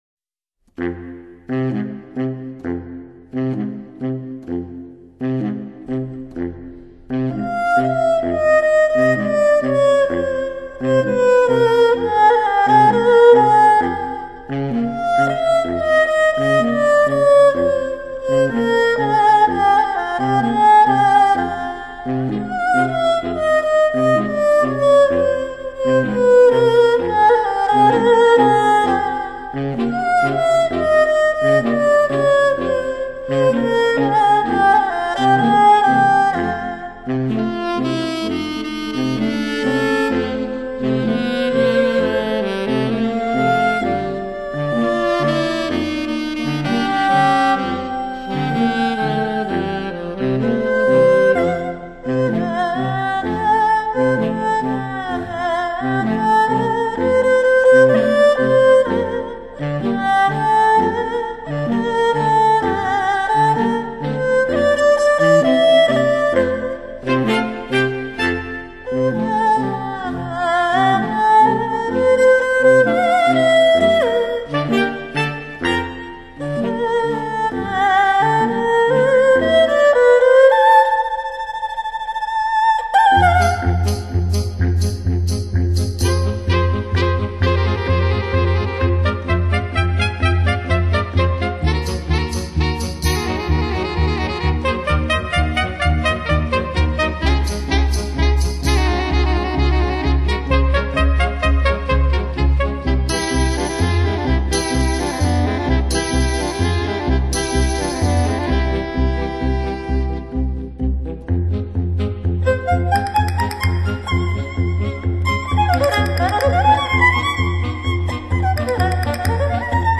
light]中西乐器合壁
萨克斯组与二胡对答交杂
铃鼓和响板倜傥错落,音色的对比赋予各自对立的角色感.